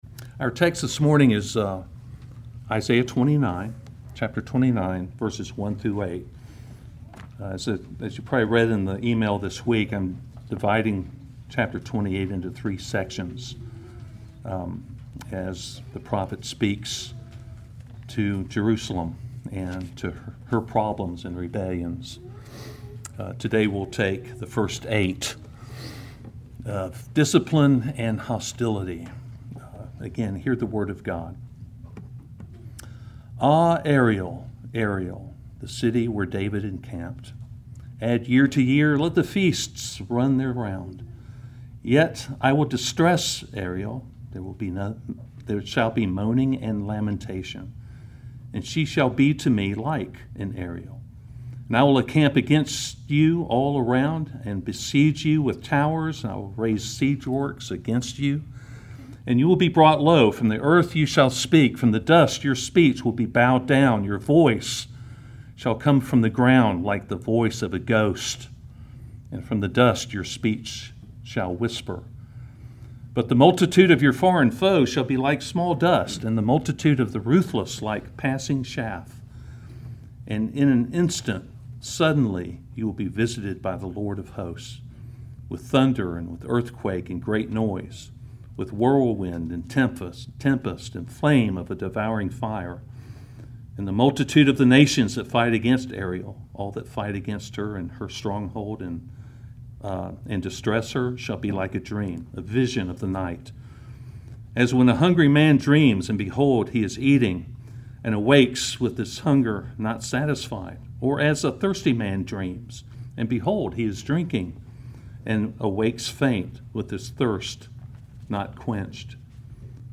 Sermons – Emmanuel Presbyterian Church